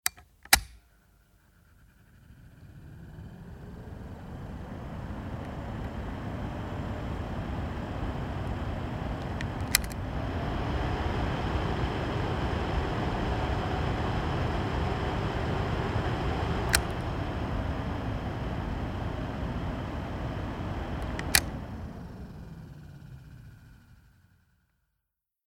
Tischlüfter "HL1"
Stufe 1-2-1